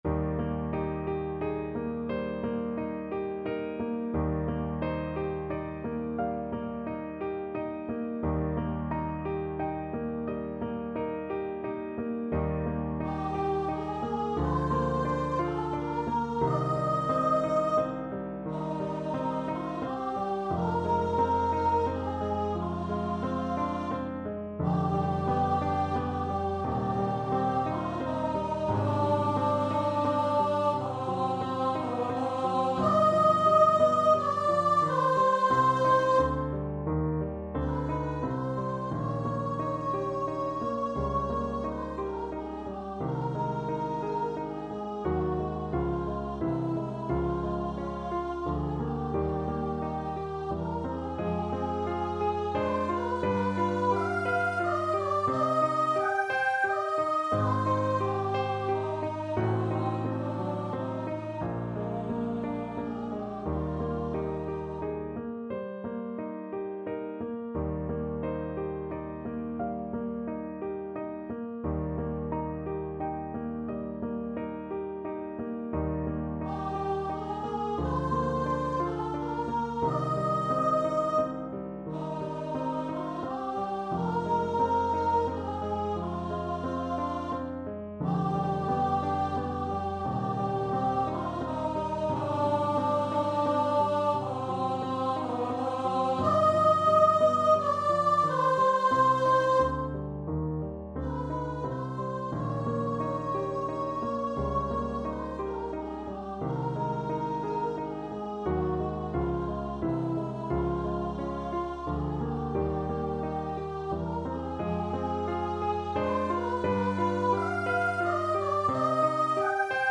~ = 88 Andante
6/4 (View more 6/4 Music)
Bb4-G6
Classical (View more Classical Voice Music)